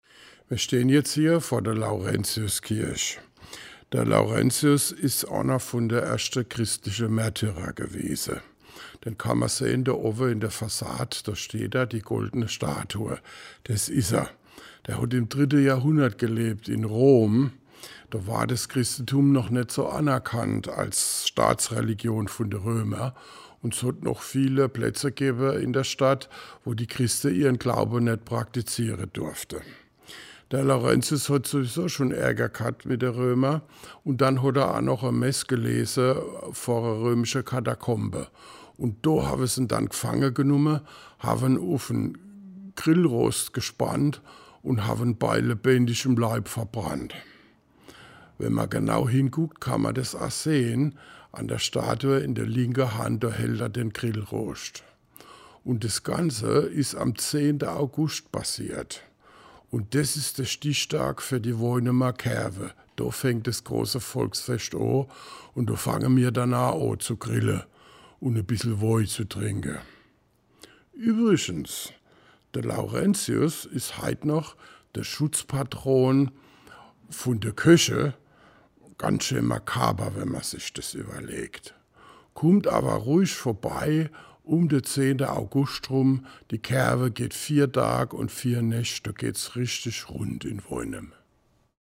Mit Mundart unterwegs in der Altstadt
An 9 Stationen in der Innenstadt können Besucher über einen QR-Code mit dem Smartphone Anekdoten in Mundart anhören.